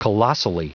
Prononciation du mot colossally en anglais (fichier audio)
Prononciation du mot : colossally